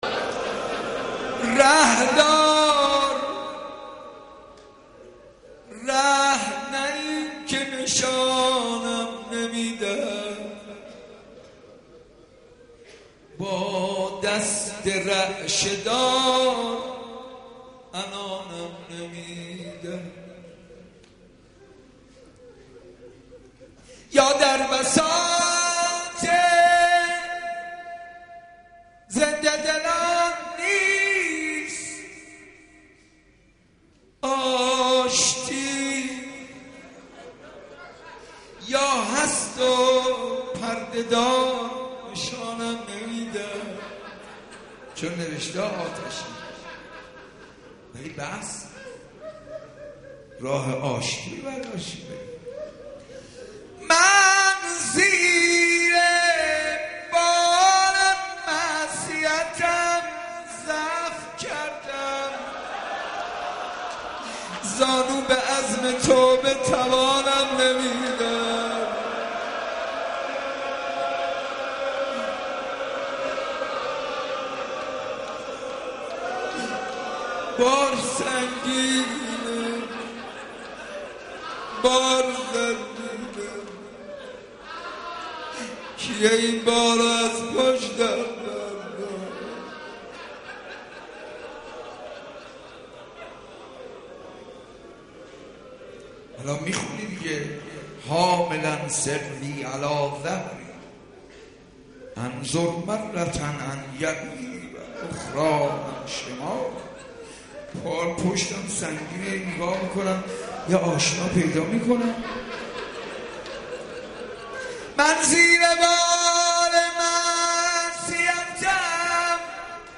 مناجات با خدا - حاج منصور ارضی